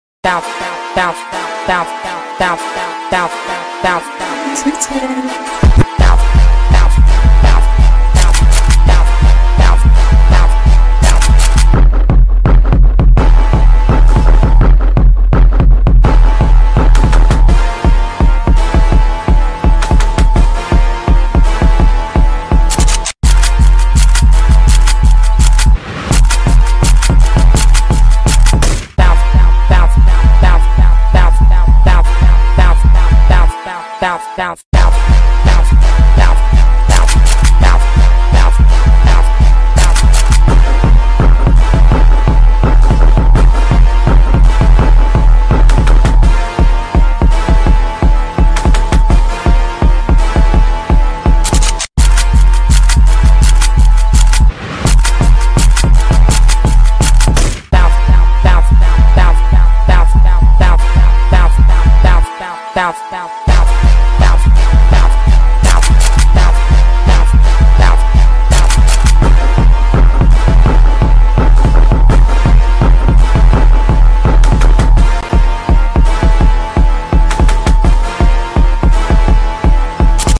Bounce 😛 sound effects free download